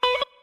50 Guitar.wav